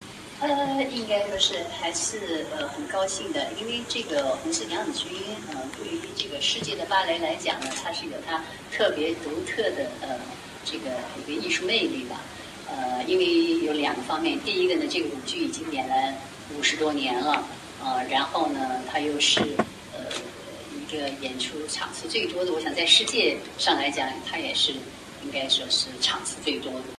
SBS记者在排练现场采访了中国芭蕾舞团团长冯英。冯英说，《红色娘子军》演出50多年了，对世界芭蕾来说有其自身独特魅力。